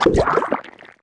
Wep Bubblegun Fire Sound Effect
wep-bubblegun-fire.mp3